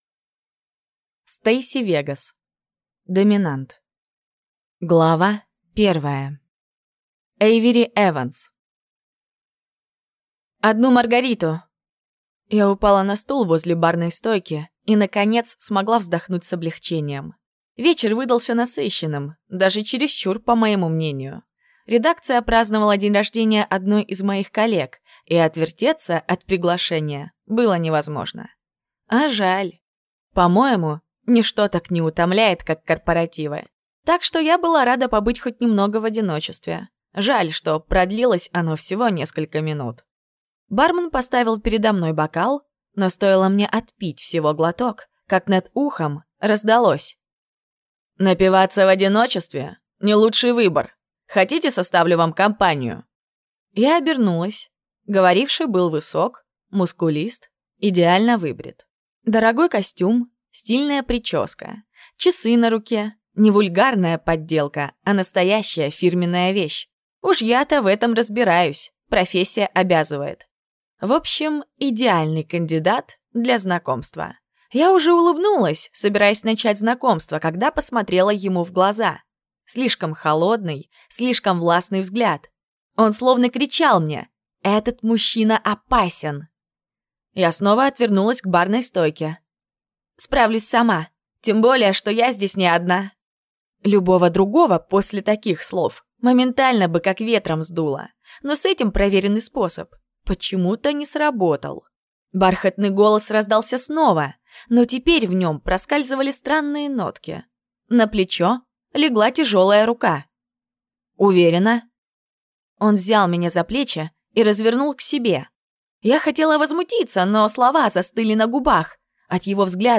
Аудиокнига Доминант | Библиотека аудиокниг
Прослушать и бесплатно скачать фрагмент аудиокниги